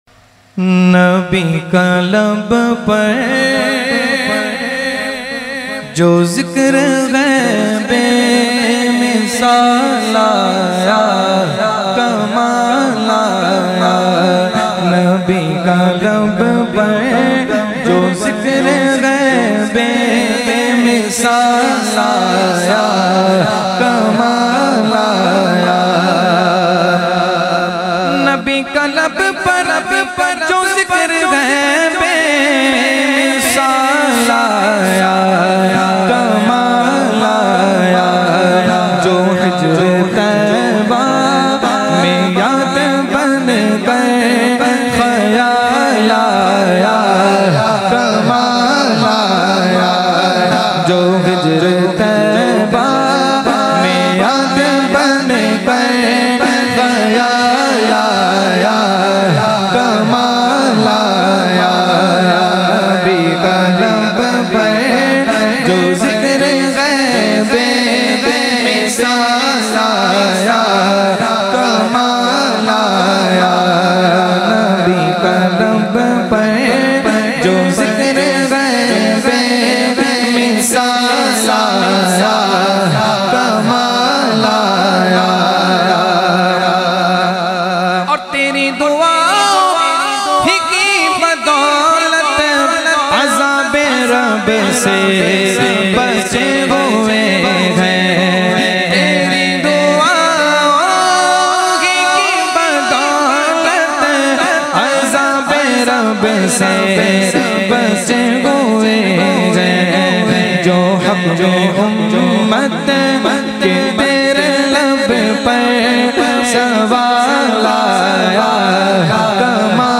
Category : Naat | Language : UrduEvent : Muharram 2020